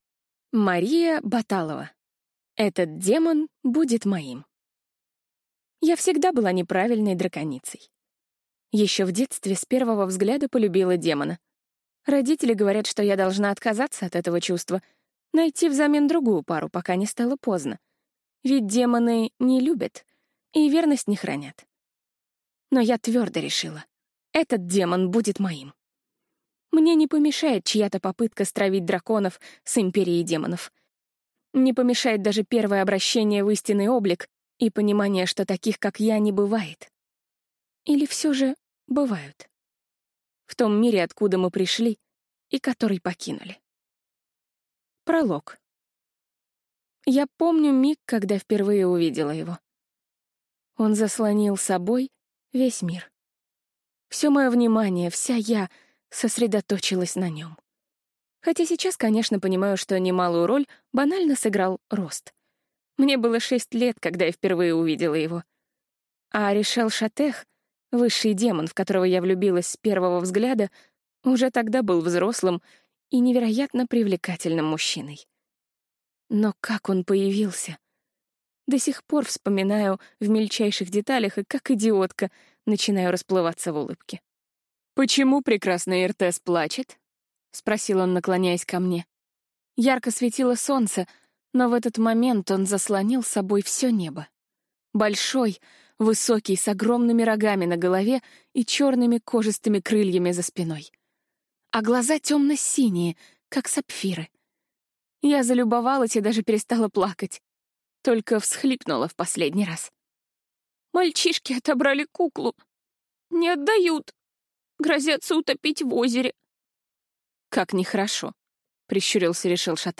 Аудиокнига Этот демон будет моим | Библиотека аудиокниг